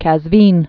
(kăz-vēn)